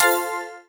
AddCoin.wav